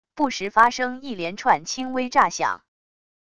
不时发生一连串轻微炸响wav音频